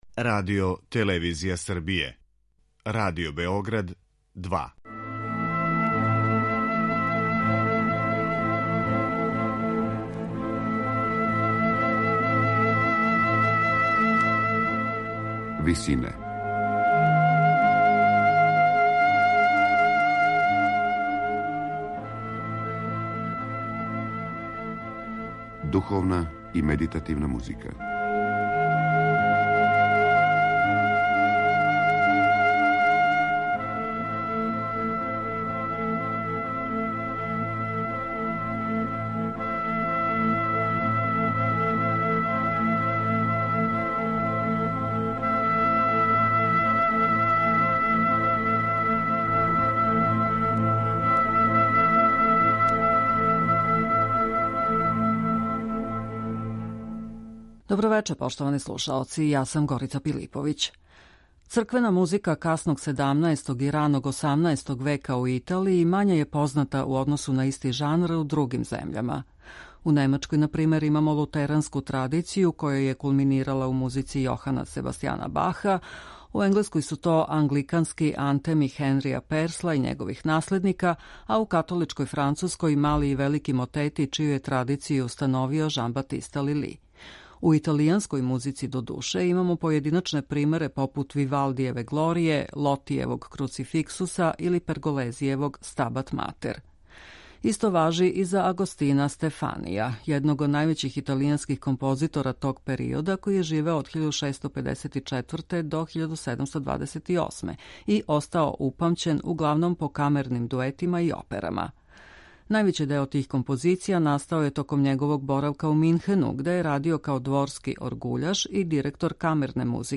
три мотета италијанског барокног аутора